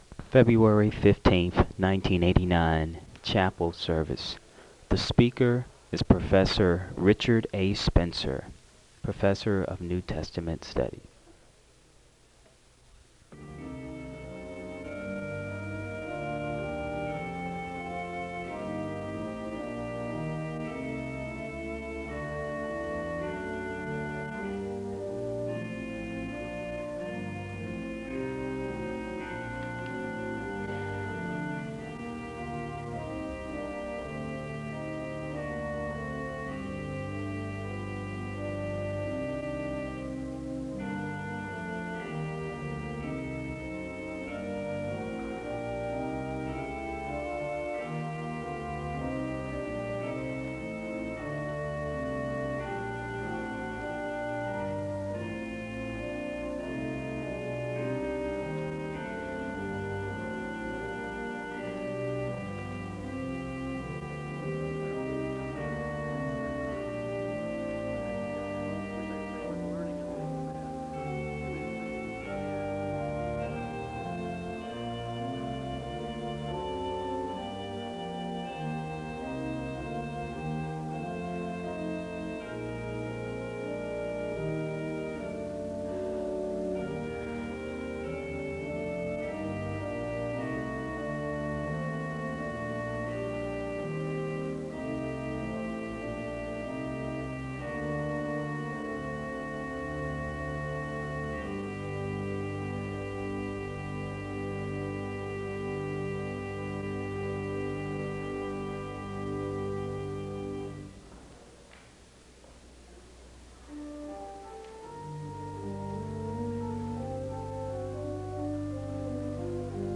A hymn is played (0:13-4:05). The congregation recites a litany of thanksgiving (4:06-5:44).
Prayer concerns are made known (12:07-13:58). A word of prayer is given (13:59-15:37).
We must anchor ourselves in God and Christ in order to remain steadfast (27:58-28:55). A hymn is sung (cut) (28:56-29:00).